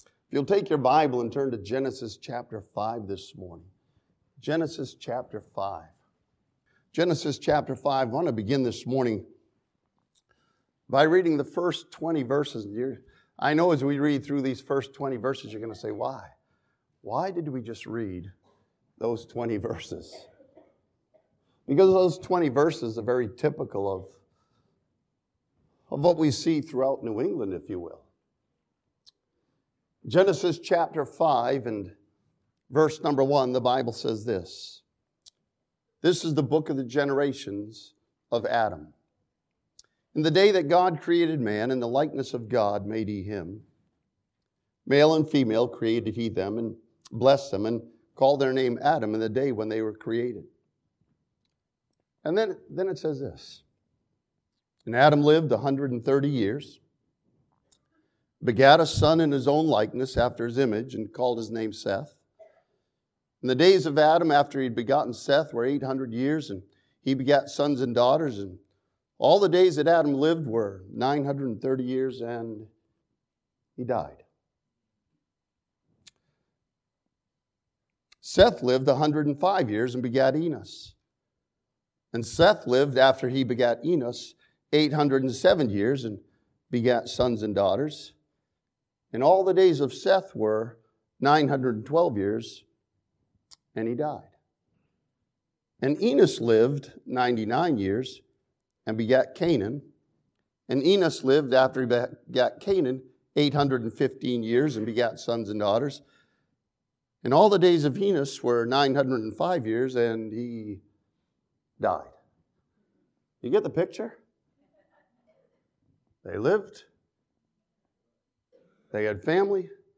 This sermon from Genesis chapter 5 challenges believers with Enoch's example of walking with God.